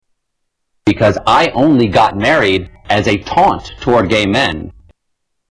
Taunt
Category: Comedians   Right: Personal